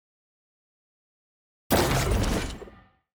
sfx-tier-wings-promotion-from-master.ogg